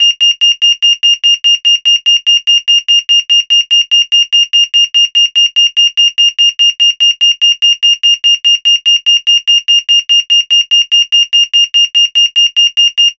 Здесь вы найдете тревожные сирены, звон разбитого стекла, шепот грабителей и другие эффектные аудиоэффекты.
Звук сирены при ограблении